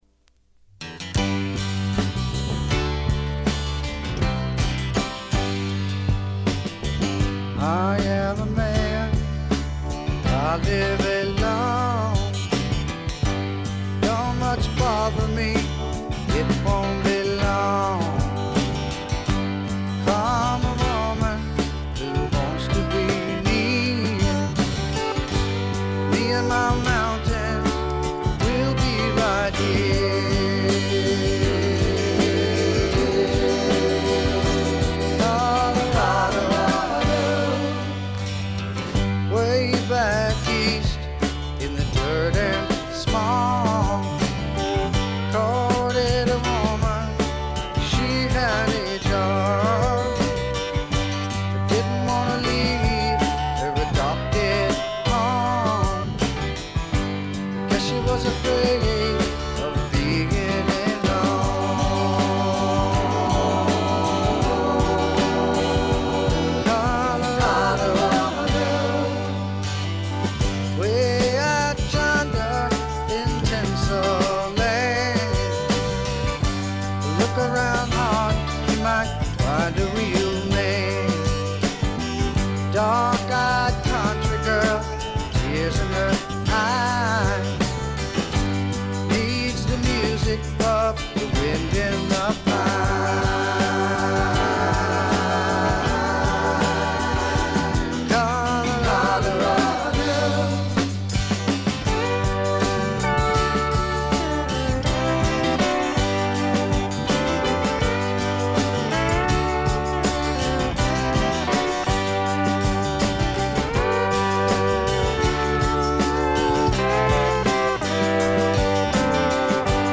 部分試聴ですがわずかなノイズ感のみ。
スローなカントリー・バラードなど様々なタイプのカントリー･ロックの見本市となっています。
試聴曲は現品からの取り込み音源です。